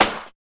88 SNARE.wav